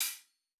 TC2 Live Hihat8.wav